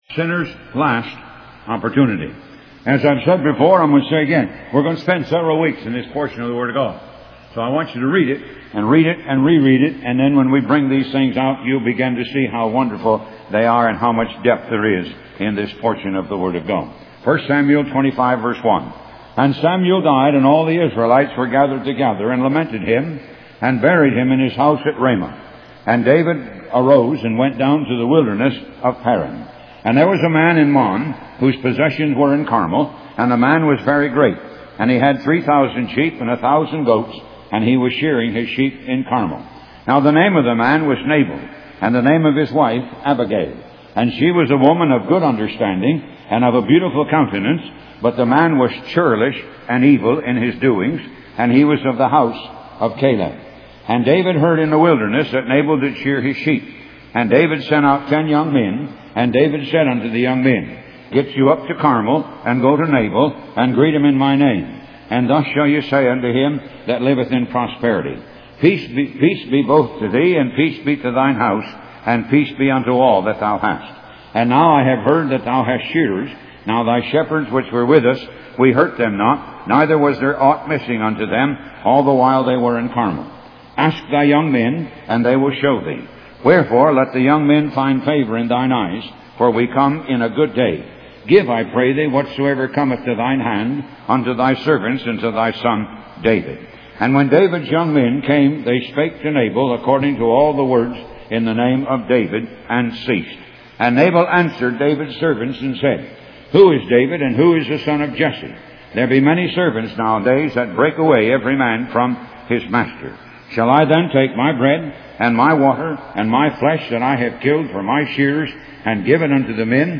Talk Show Episode, Audio Podcast, Moga - Mercies of God Association and Sinners Last Opportunity on , show guests , about Sinners Last Opportunity, categorized as History,Philosophy,Religion,Christianity,Society and Culture